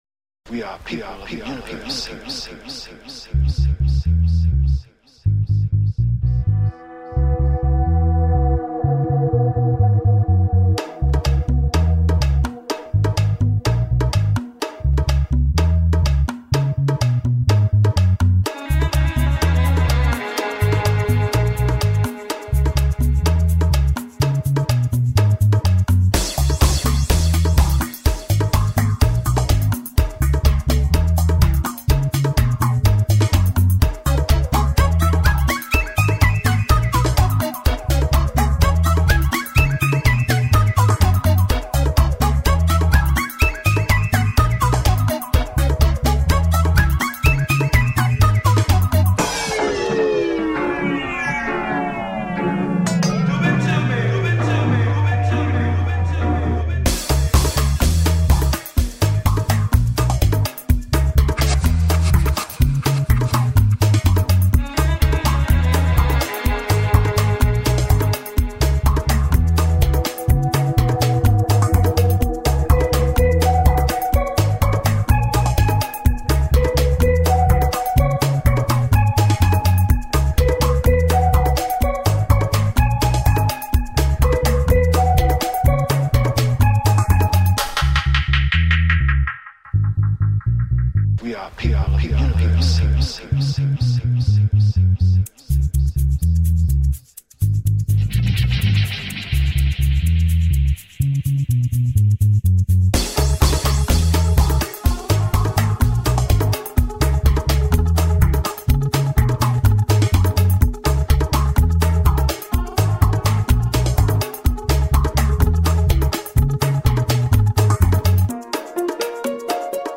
Super fine dub .
Positive, laid back, deeply cool.
Tagged as: Electronica, World, Reggae, Dub